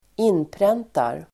Uttal: [²'in:pren:tar]